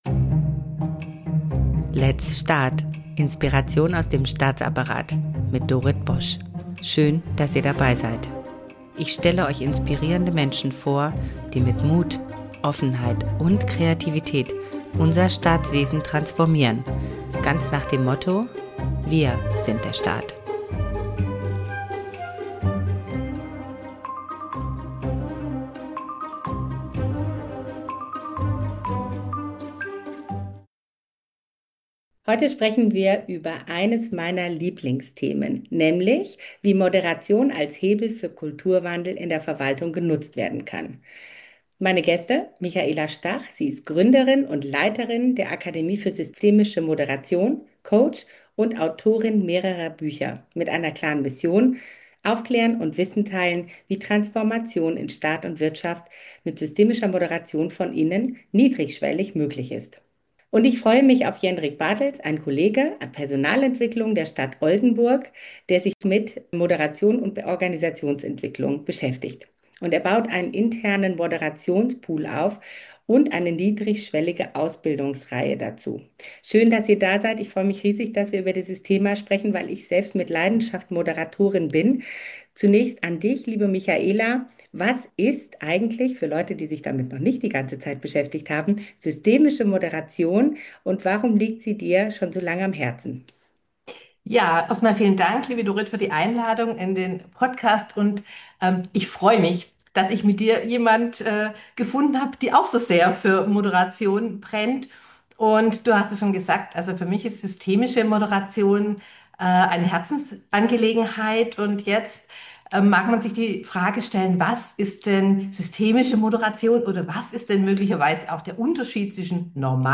In dieser Folge spreche ich mit zwei außergewöhnlichen Gästen über eines meiner absoluten Herzensthemen: systemische Moderat...